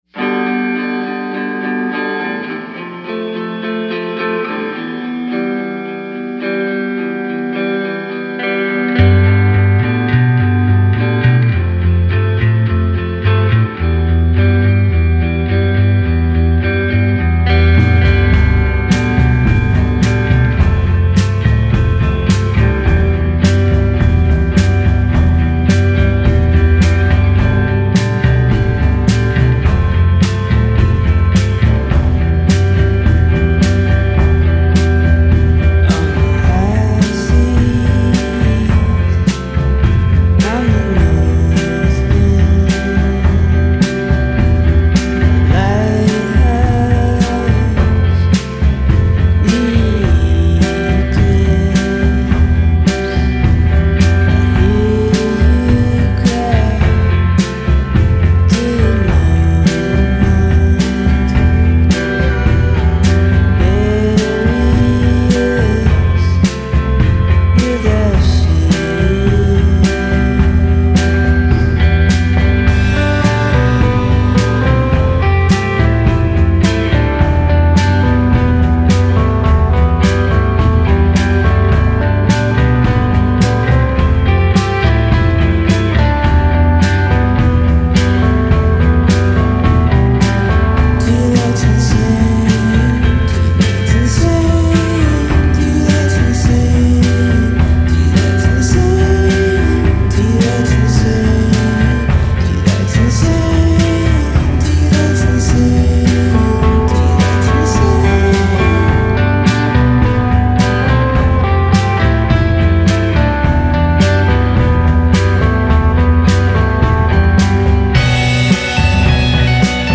spaced out mid-fi, fuzzed out sounds